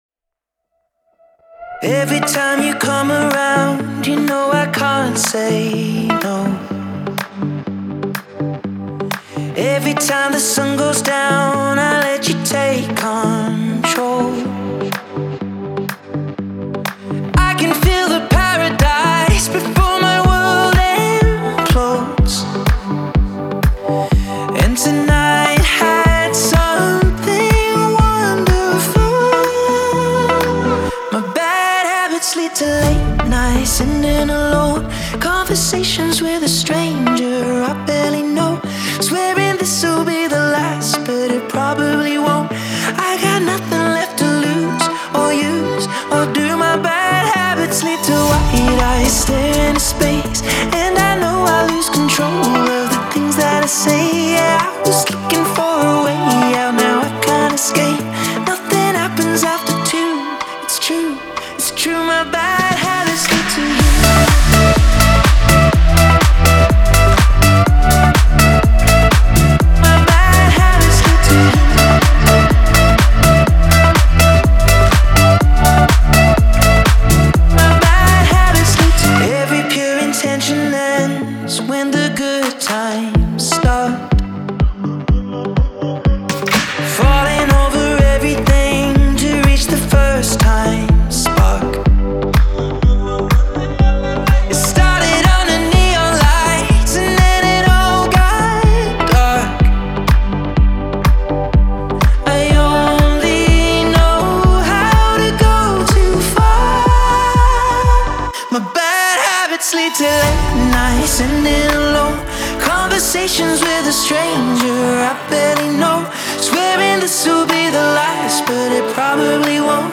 яркая поп-песня
наполненная энергией и ритмичными битами.